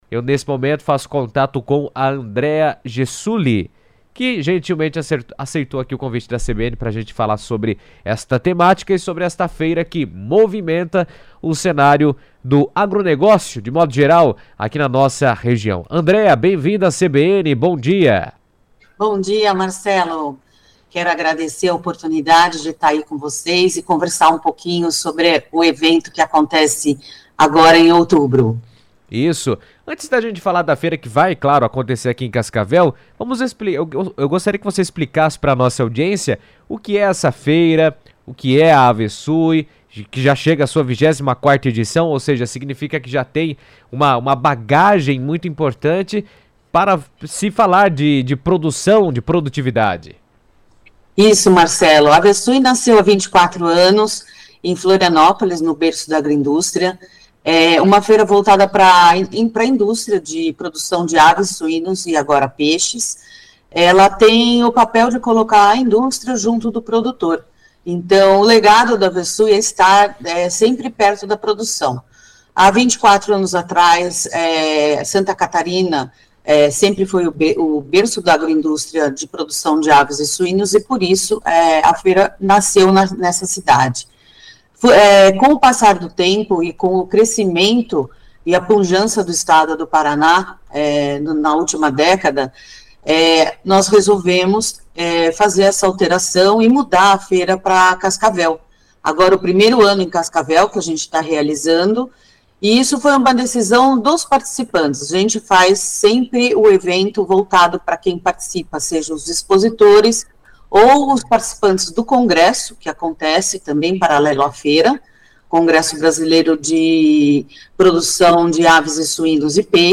A Feira da Indústria Latino-Americana de Aves, Suínos e Peixes (Avesui) será realizada em Cascavel entre os dias 28 e 30 de outubro, reunindo empresas, especialistas e produtores do setor de proteína animal em busca de inovação, negócios e integração de mercado. Em entrevista à CBN